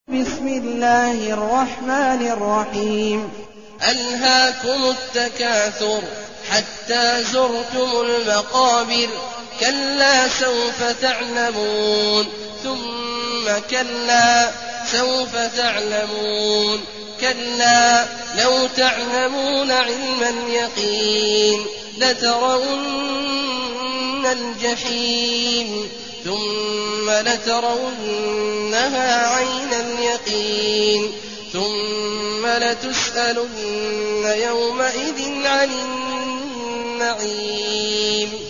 المكان: المسجد الحرام الشيخ: عبد الله عواد الجهني عبد الله عواد الجهني التكاثر